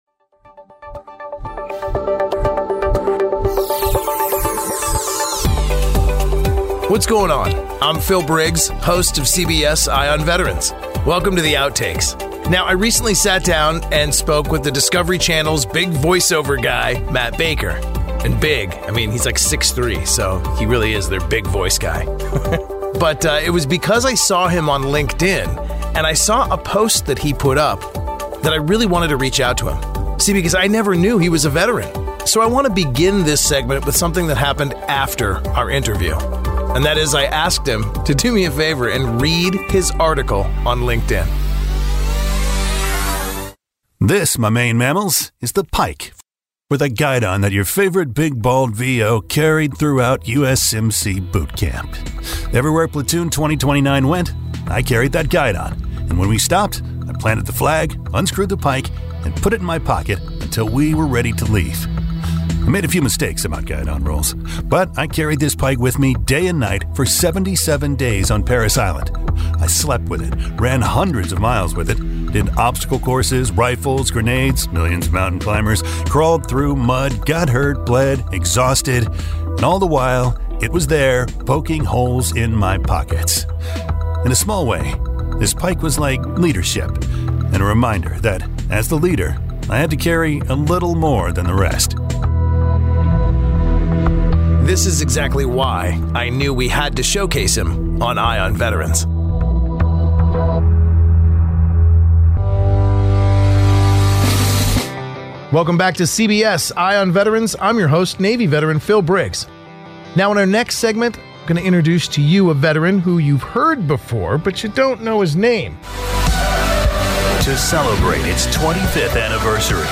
In our interview